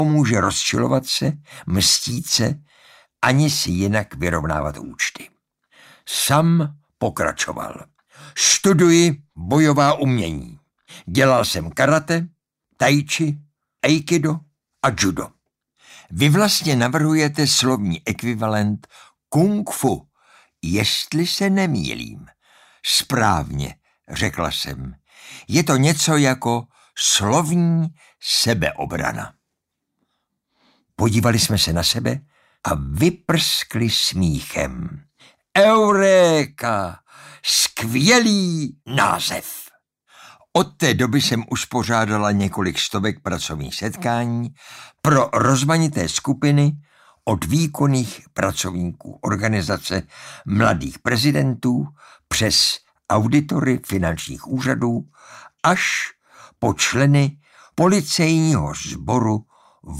Audiobook
Read: Jan Přeučil